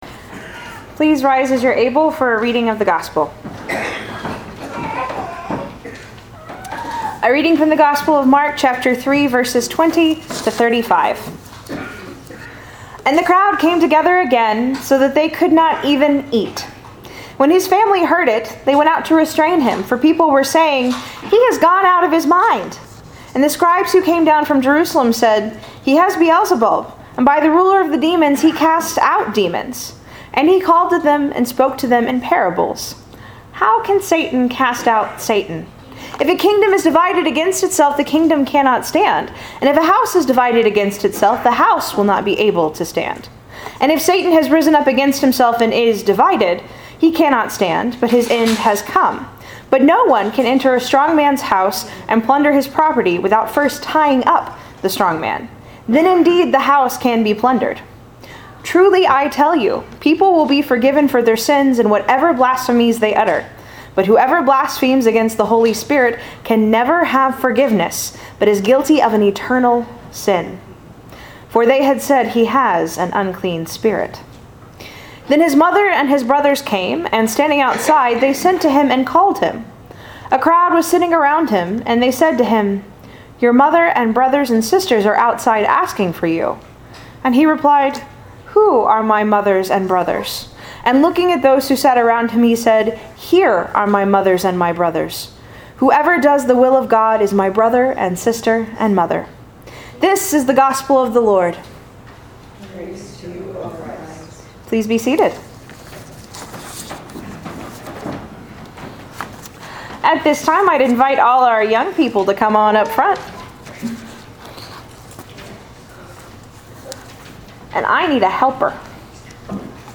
SErmonJune6.MP3